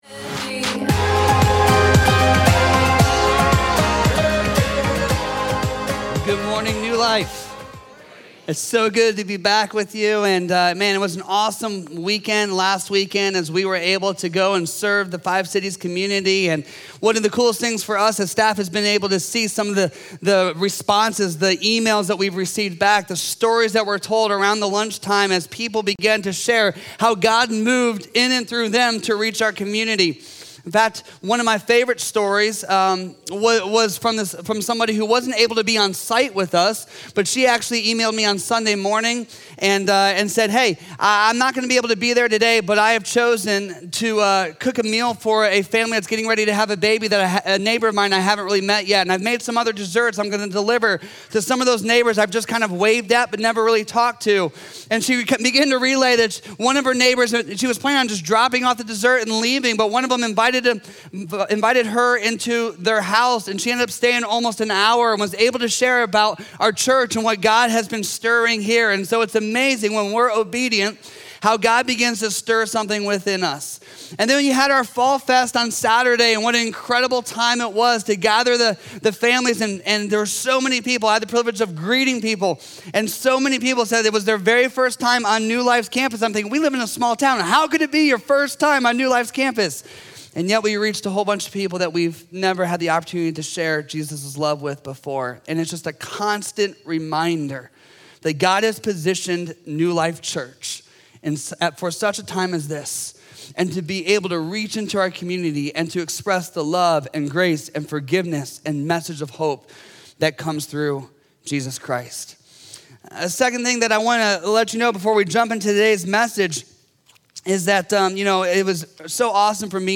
A message from the series "Believe and Have Life."